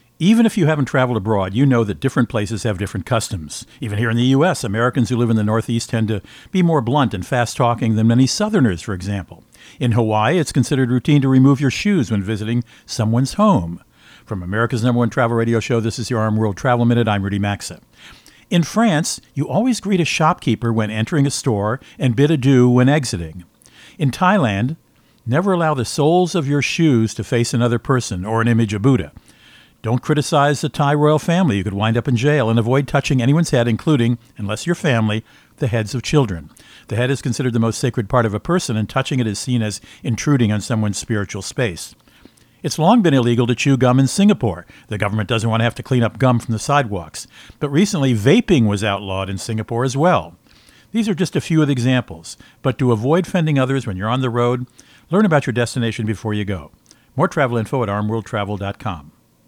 Co-Host Rudy Maxa | Local Customs that lean Unusual